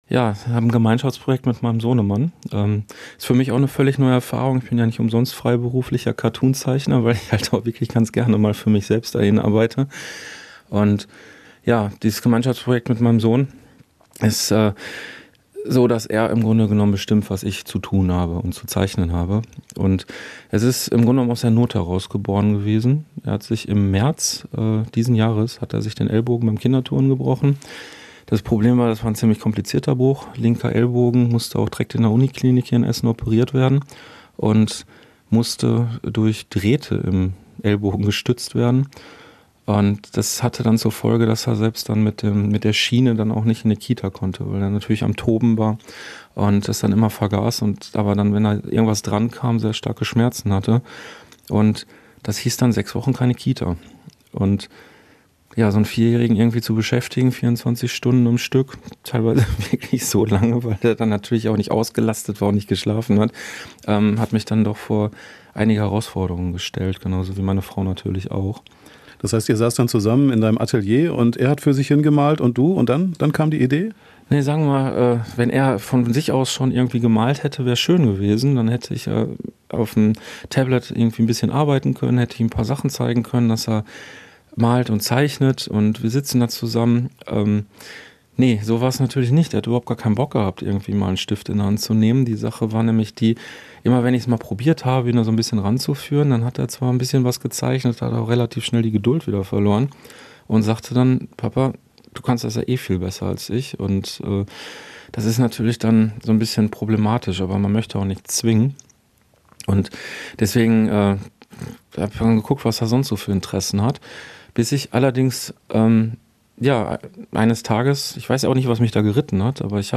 Wir haben die Künstler Zuhause in Frohnhausen besucht.
Im Interview